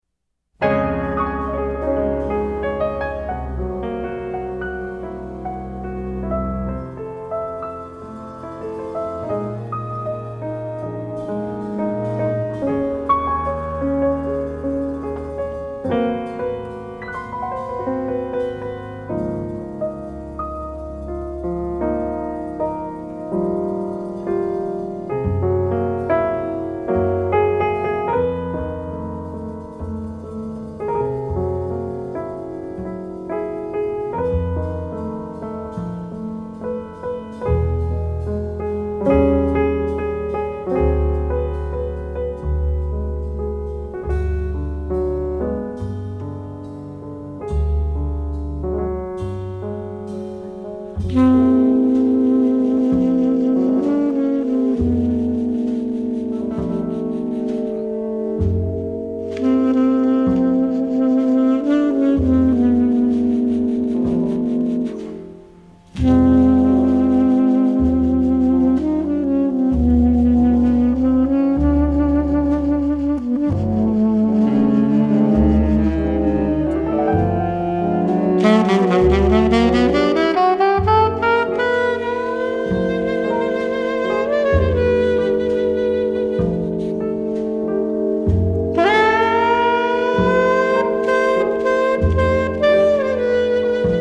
頂尖的樂團、一流的編曲，加上動人的演唱